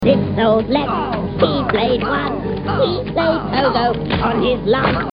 Category: Movies   Right: Personal